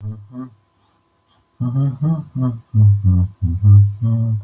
Vorgelesen von echten Menschen – nicht von Computern.